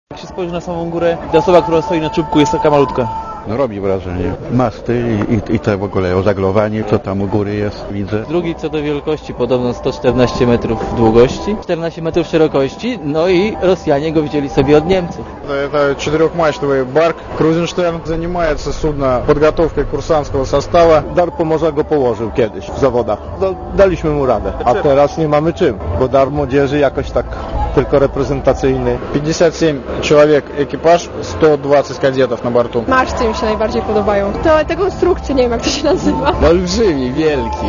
Komentarz audio "Kruzensztern" powstał w 1926 roku.